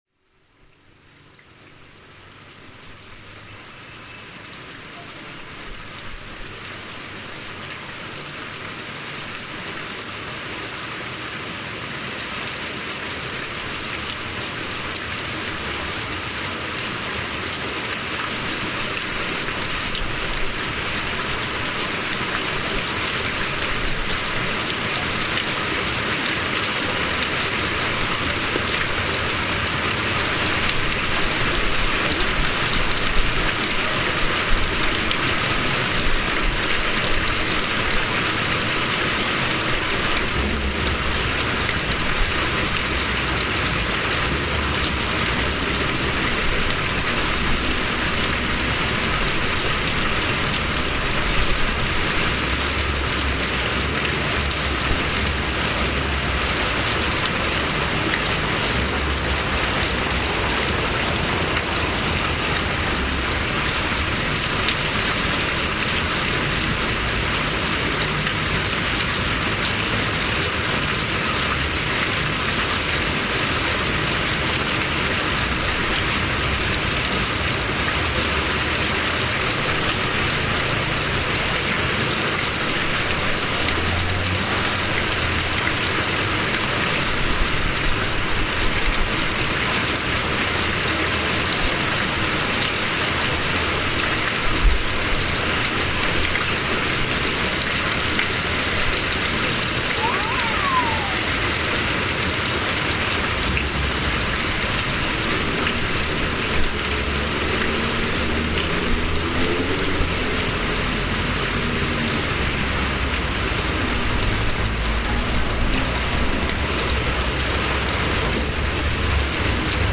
Se encuentra en uno de los cuatro barrios más importantes de Comitán de Domínguez, municipio de Chiapas, que pertenece a la Región III Fronteriza.
Está a un costado de la iglesia de San Caralampio es un santo ortodoxo muy famoso, en el santoral católico no existe, en griego su nombre es Xarálambos (algo que da mucha alegría, luz resplandeciente de Xará, alegría y Lambí, brillar).